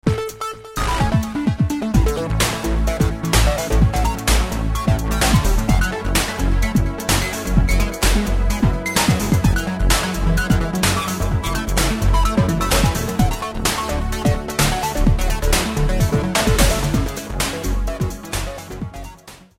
Metoda ta nie generuje dźwięków brzmiących realistycznie, lecz dzięki dodatkowemu stosowaniu zabiegów modulacujnych (LFO, zmiany obwiedni) możliwe jest uzyskanie ciekawych brzmień syntetycznych.
Przykładowe syntezatory wykorzystujące syntezę wavetable.